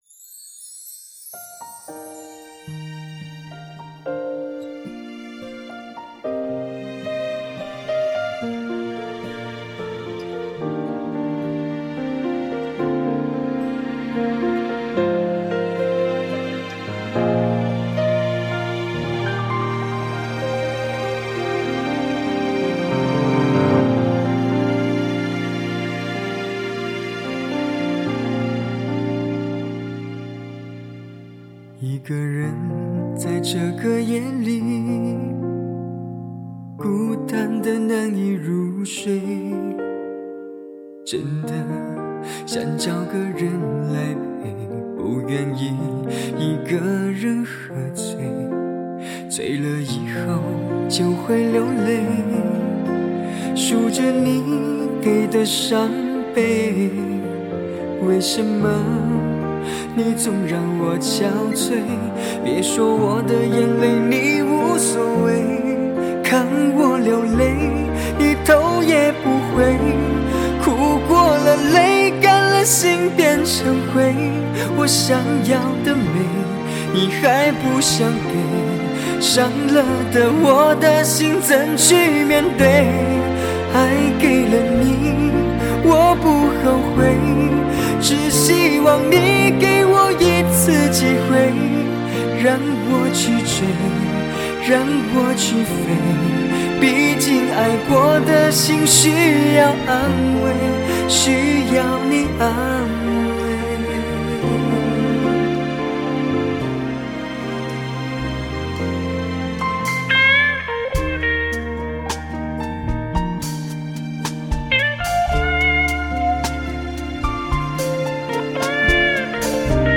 无损黑胶 德国技术1:1灌录 3CD
德国黑胶母盘1:1技术 品质高清